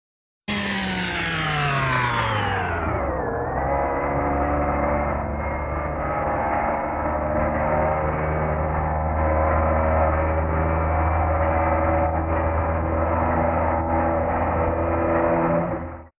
BSG FX - Viper Blast Off Whine - Winding down
BSG_FX_-_Viper_Blast_Off_Whine_-_Winding_down.wav